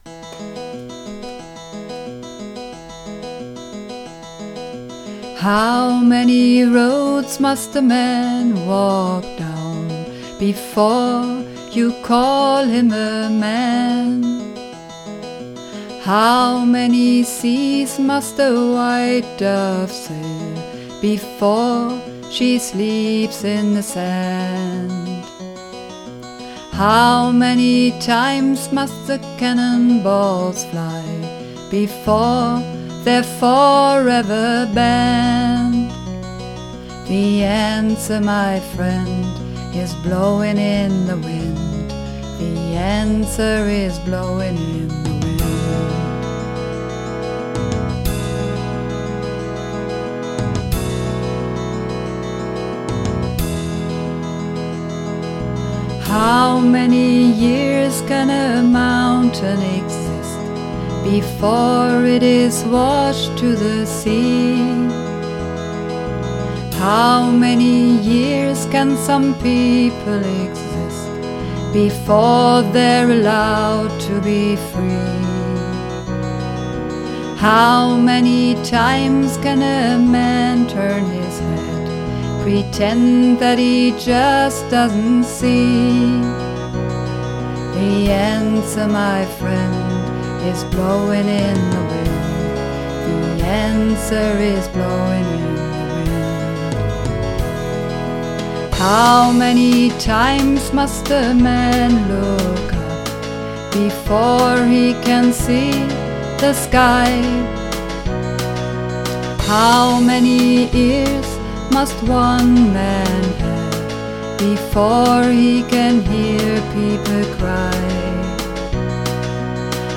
Übungsaufnahmen
Runterladen (Mit rechter Maustaste anklicken, Menübefehl auswählen)   Blowin' In The Wind (Bass)
Blowin_In_The_Wind__2_Bass.mp3